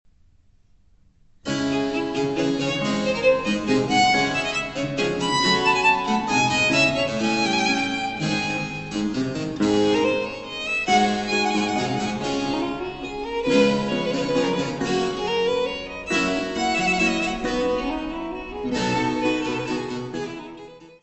: stereo; 12 cm
St. Andrew's Church, Toddington
violino barroco
alaúde, tiorba e guitarra
cravo
Área:  Música Clássica